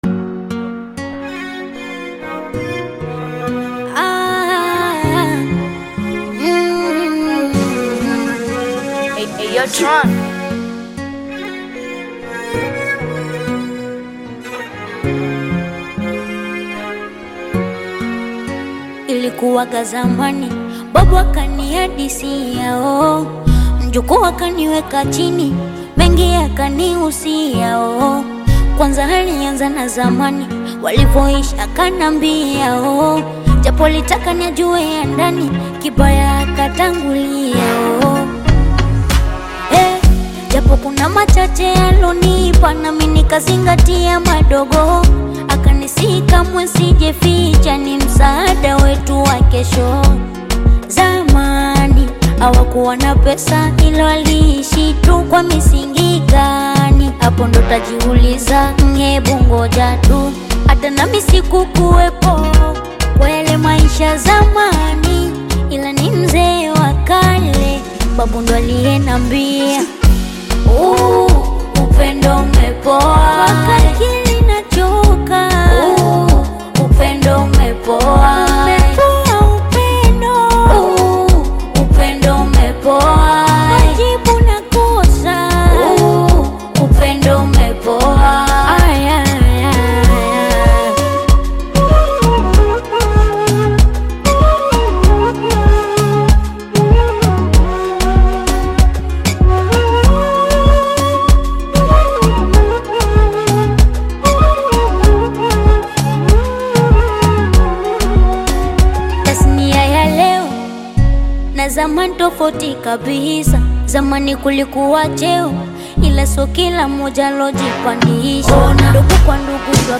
Bongo Flava music track
Tanzanian Bongo Flava artist and rapper